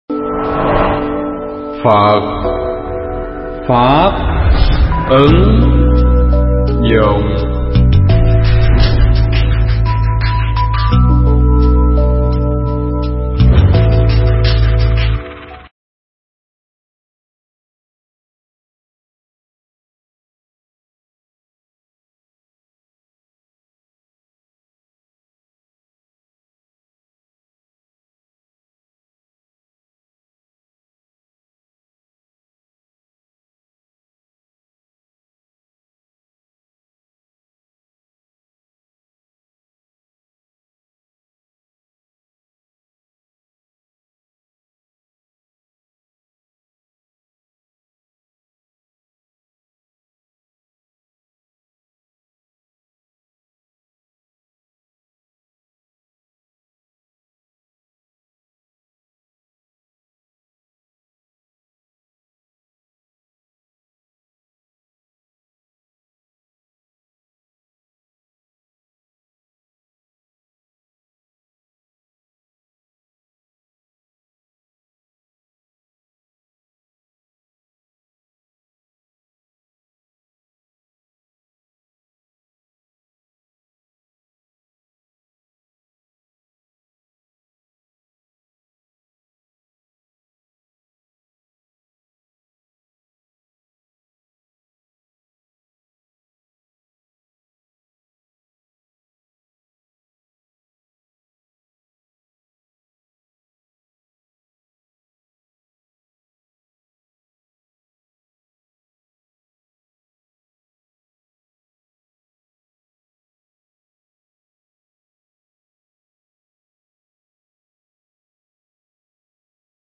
Mp3 Thuyết Pháp Giác Ngộ Vô Thường (Enlightenment Of The Impermanence)
giảng tại chùa Nhị Mỹ